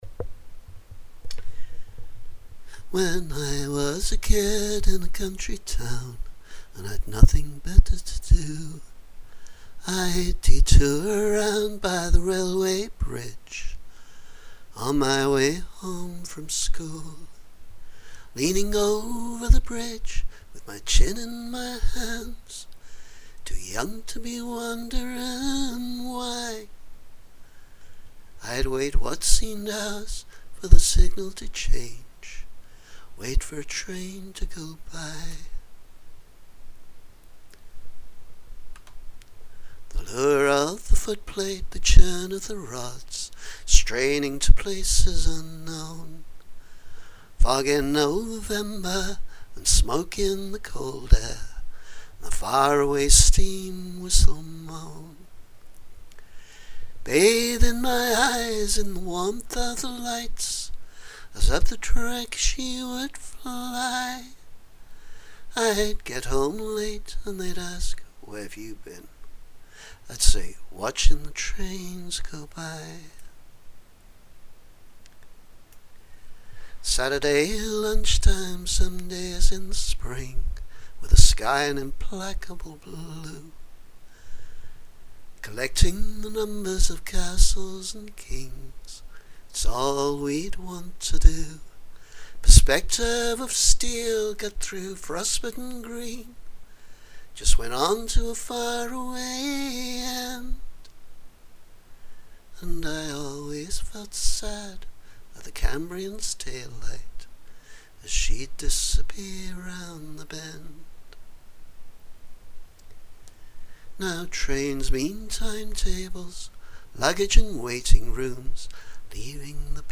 Solo version.